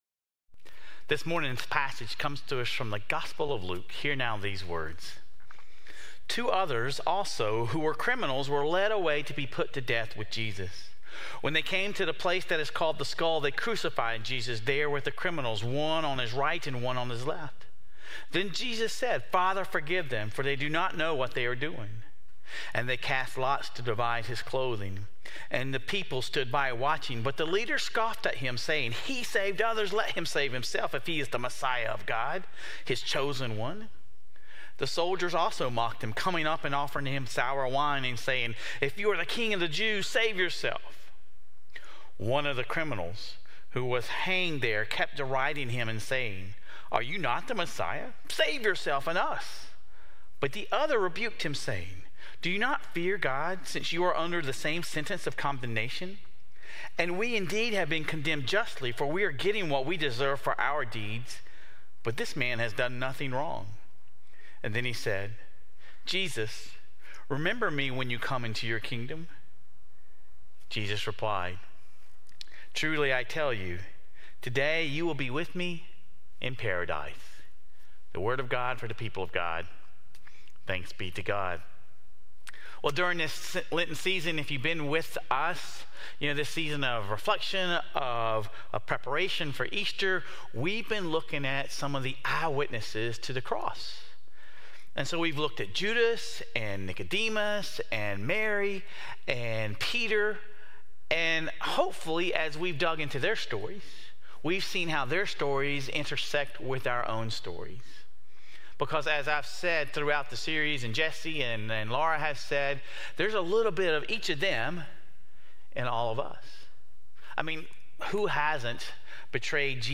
This week, we hear the story of the two thieves crucified alongside Jesus. Sermon Reflections: Which criminal on the cross do you identify with more?